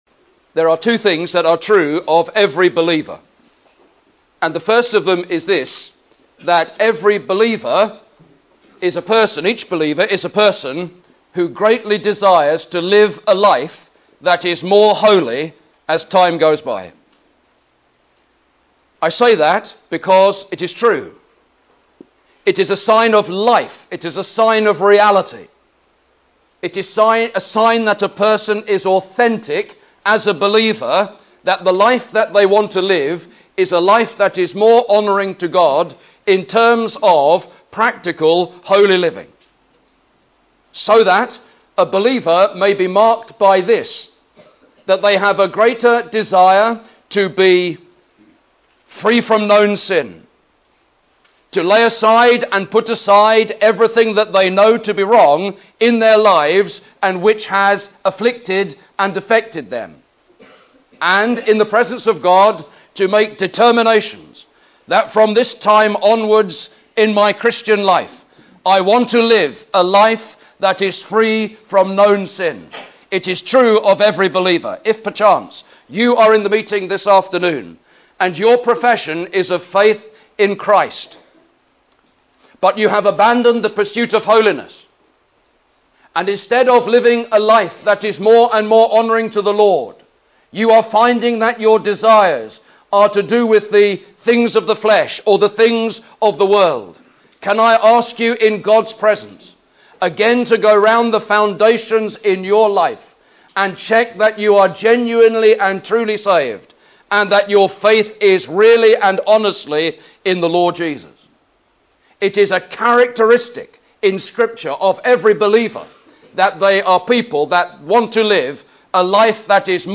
2011 Easter Conference
Save Easter Conference 2011